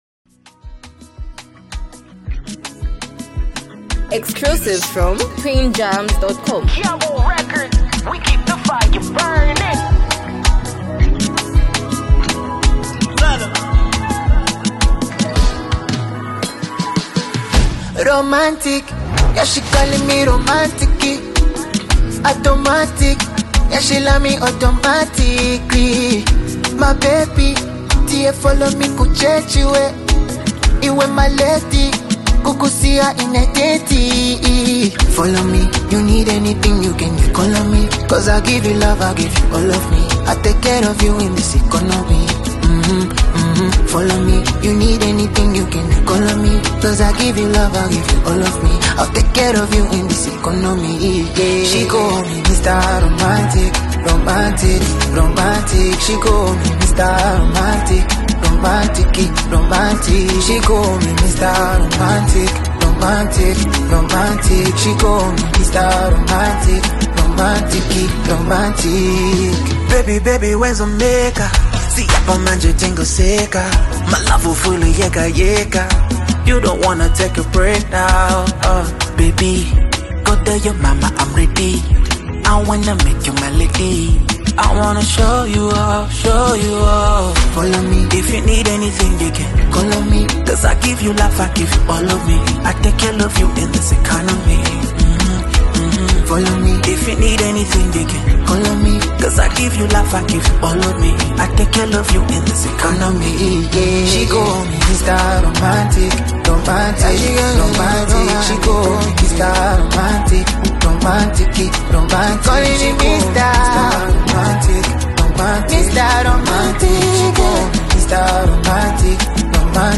smooth and heartfelt love song
signature soulful vocals
melodic verse that adds another layer of emotion